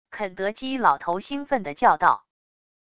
肯德基老头兴奋的叫道wav音频